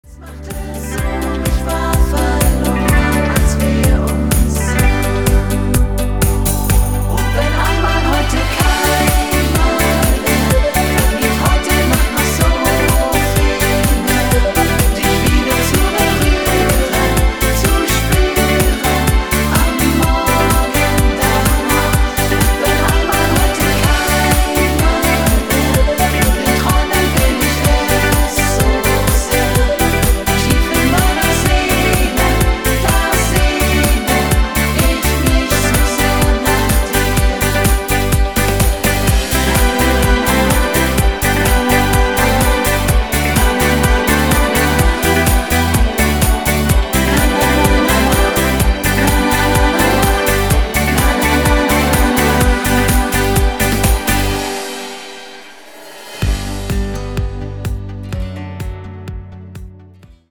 Art: Aktuelle Chart-Titel
sehr schönes Duett